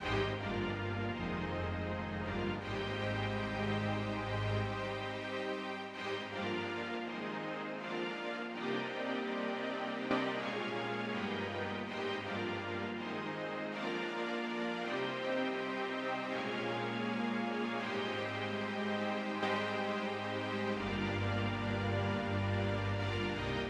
11 strings 1 C.wav